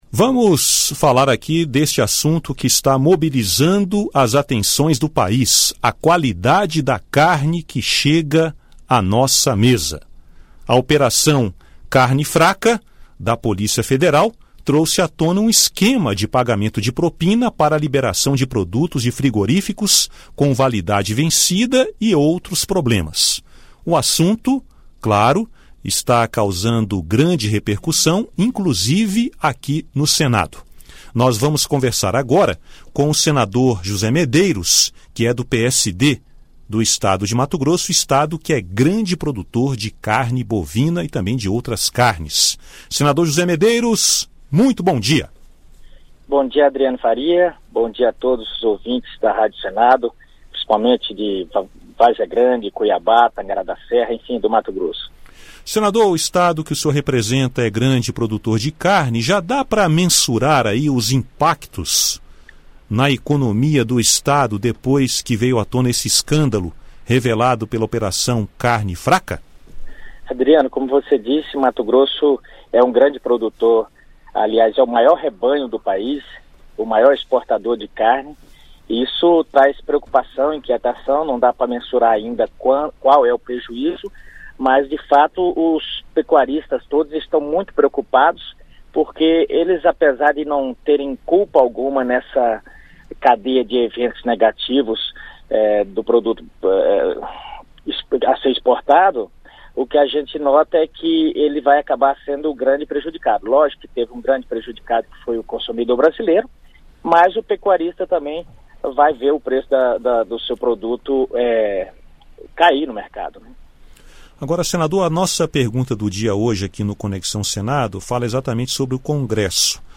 Vice-líder do governo no Senado, o senador José Medeiros (PSD-MT) afirmou nesta terça-feira (21), em entrevista à Rádio Senado, que considera desnecessário o Congresso Nacional criar uma Comissão Parlamentar de Inquérito (CPI) para investigar as denúncias de irregularidades em frigoríficos e pagamento de propina a fiscais do Ministério da Agricultura, Pecuária e Abastecimento (Mapa). José Medeiros argumentou que as investigações já estão sendo conduzidas pela Polícia Federal por meio da Operação Carne Fraca.